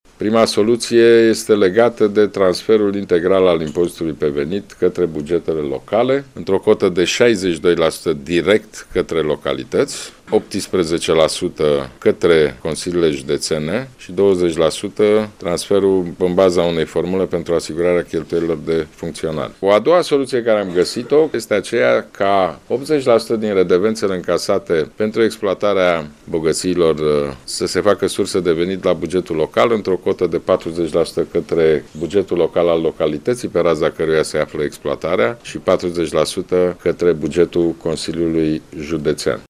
PNL propune un proiect de lege prin care administrațiile publice locale să beneficieze de fondurile necesare pentru o descentralizare și o autonomie mai mari. Potrivit documentului, impozitul pe venit și o mare parte din redevențe ar urma să ajungă direct în bugetele locale, a precizat, ieri, la Iași, liderul liberal, Ludovic Orban.